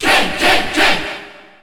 Ken_Cheer_Japanese_SSBU.ogg.mp3